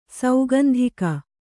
♪ saugandhika